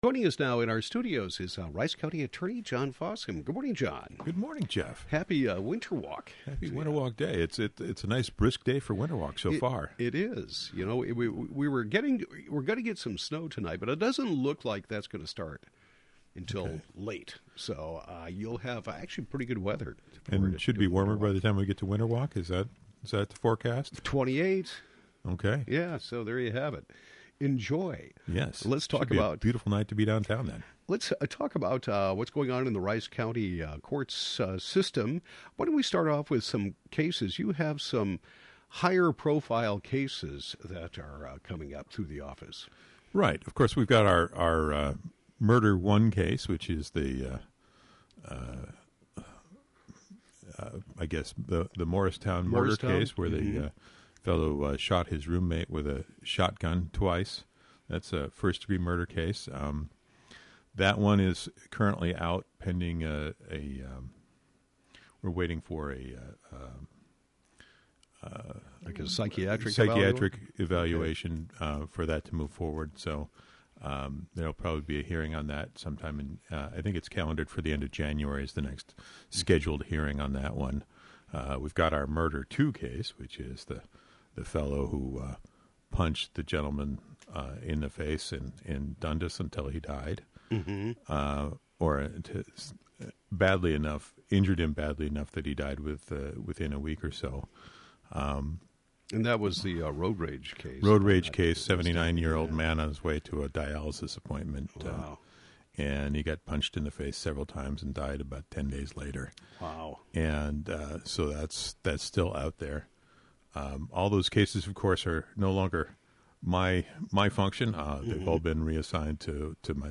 Rice County Attorney John Fossum discusses pending murder cases and legislative issues